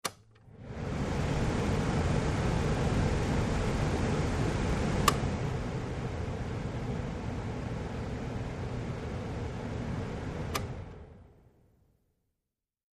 Air Conditioner 2; Air Conditioner Blows; On Switch Followed By High Speed Air Blows, A Speed Switch, Low Speed Air Blows, And Then Switched Off. Close Perspective. Fan.